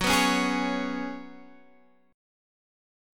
F#sus2sus4 chord